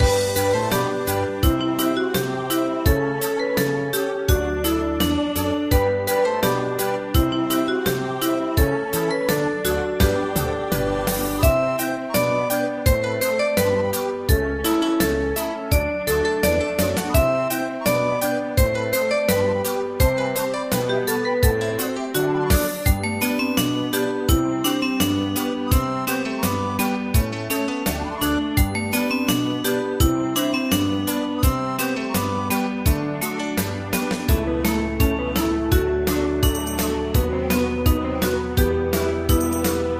Ensemble musical score and practice for data.